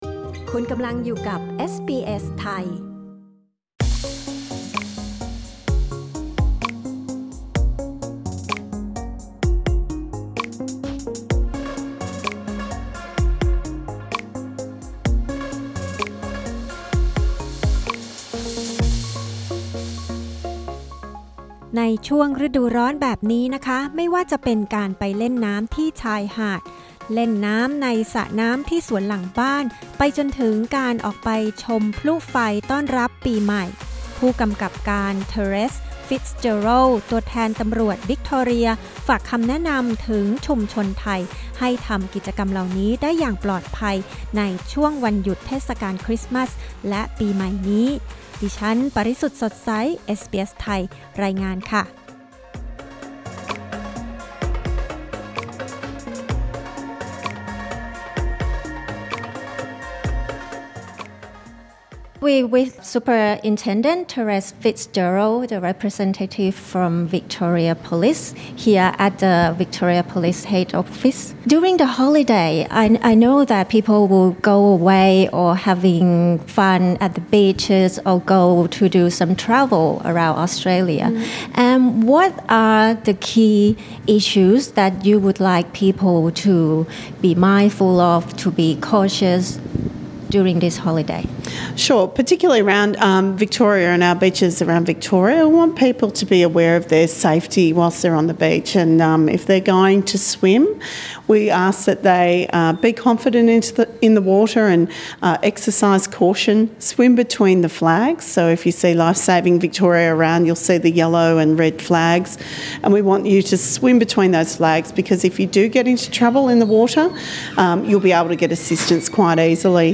NEWS: ไม่ว่าจะเป็นการไปเล่นน้ำที่ชายทะเล เล่นน้ำในสระน้ำที่สวนหลังบ้าน ไปจนถึงการออกไปชมพลุต้อนรับปีใหม่ ตำรวจวิกตอเรียฝากคำแนะนำถึงชุมชนไทย ให้ทำกิจกรรมเหล่านี้ได้อย่างปลอดภัยช่วงวันหยุดเทศกาลคริสต์มาสและปีใหม่นี้